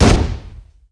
1 channel
canon_machine_03.mp3